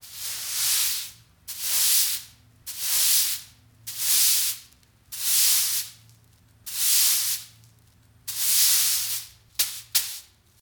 broom.ogg